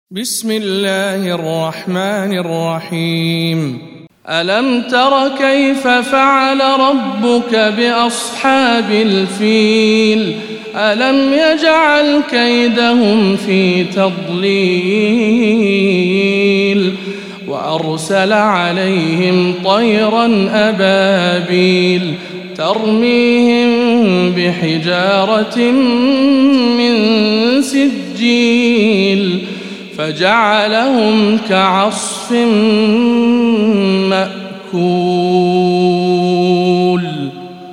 سورة الفيل - رواية ابن ذكوان عن ابن عامر